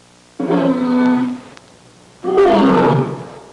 Happy Lion Sound Effect
Download a high-quality happy lion sound effect.
happy-lion.mp3